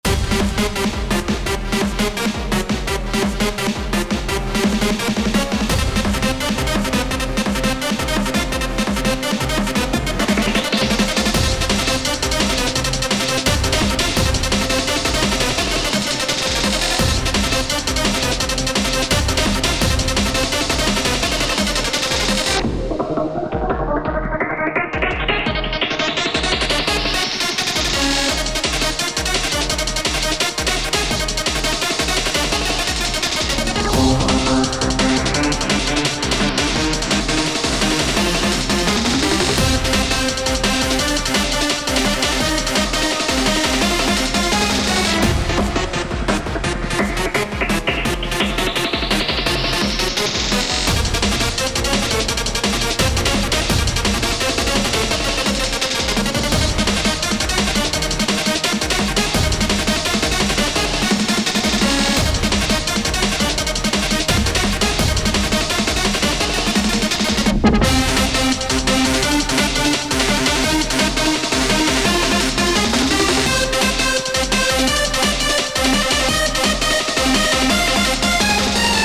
💬荒々しく攻撃的なキャラクター（敵）をイメージした曲です。